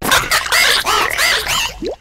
quaquaval_ambient.ogg